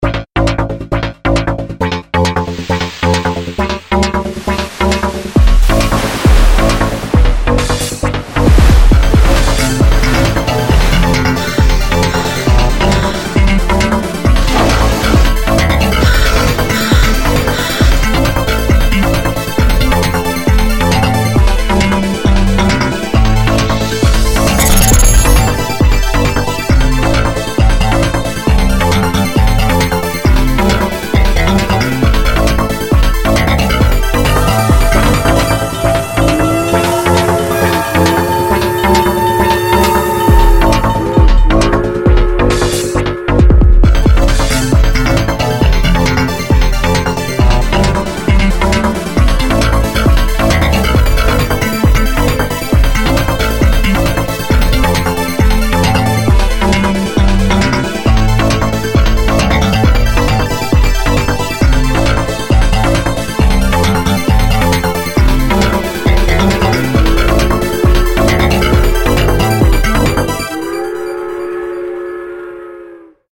electro-funk